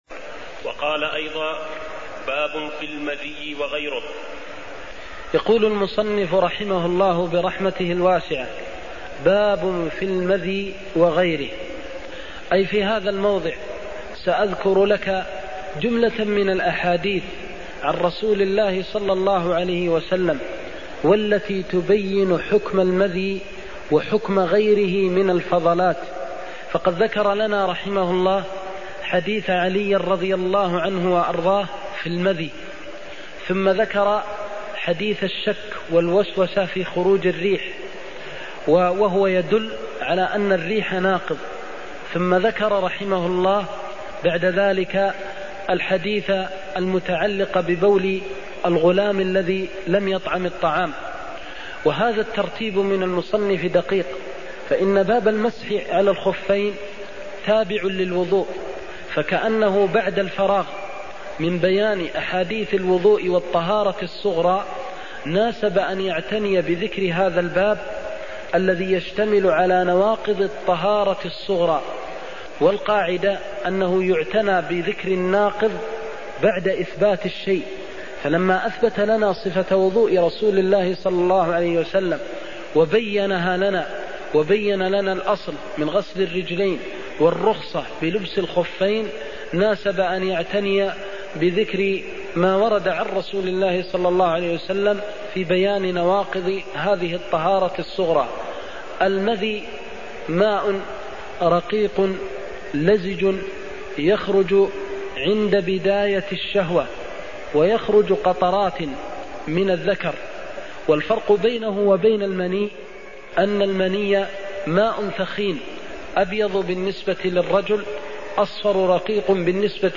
المكان: المسجد النبوي الشيخ: فضيلة الشيخ د. محمد بن محمد المختار فضيلة الشيخ د. محمد بن محمد المختار حكم الطهارة من المذي (24)